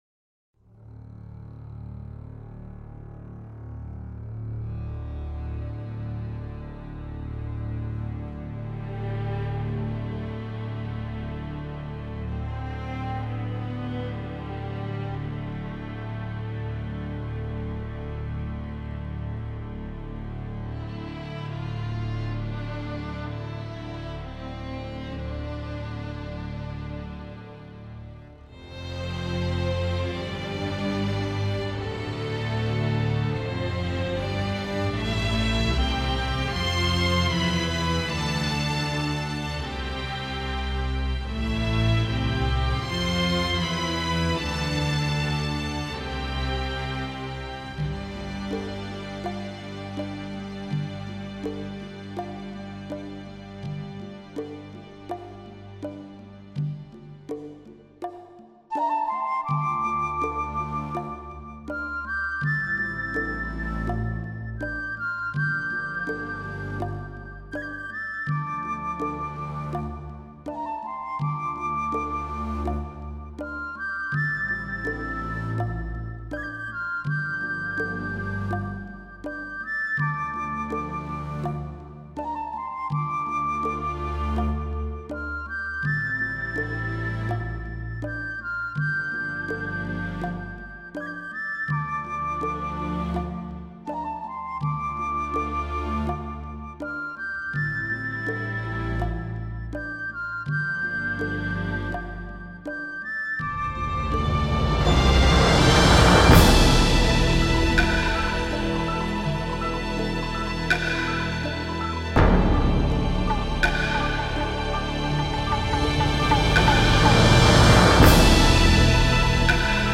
随着历史叙述的起伏不断变换着节奏，低沉的打击乐仿佛敲响了永乐朝的大钟